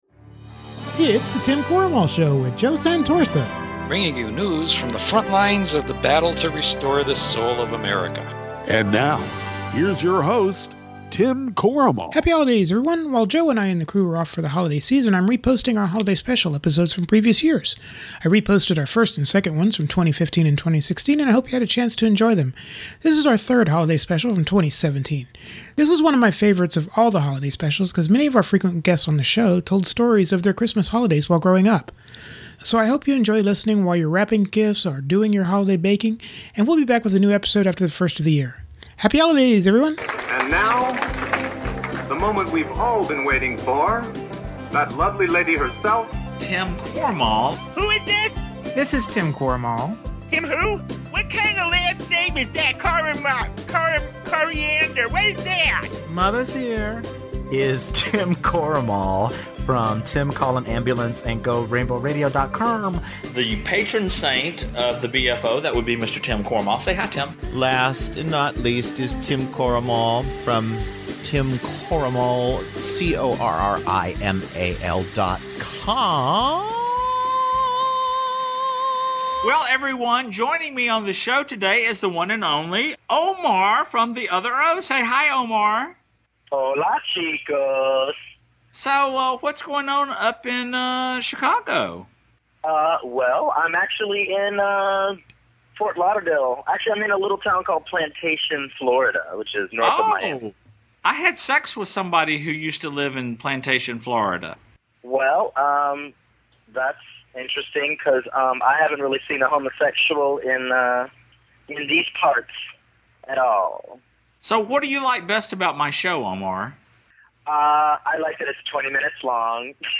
many of our frequent podcast guests tell stories of Holiday’s past